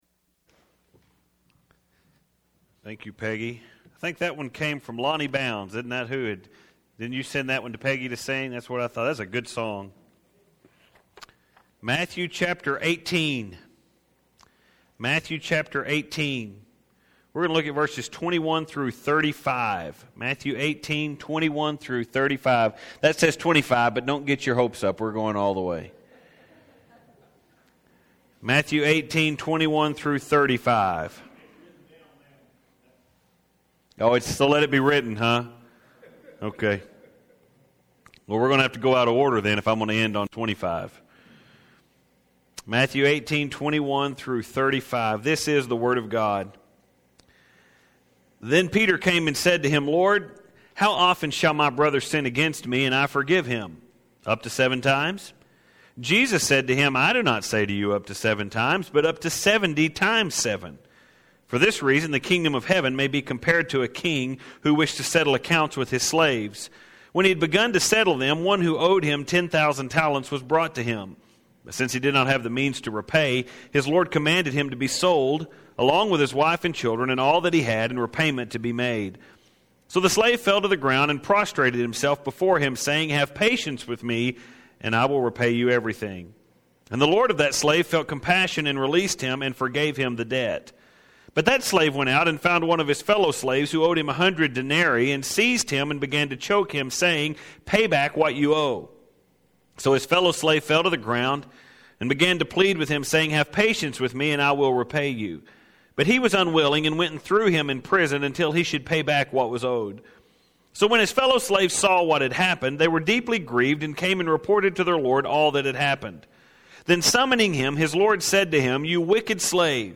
As you know we are presently studying the sermon of Jesus In regard to how those in the church are to relate to one another.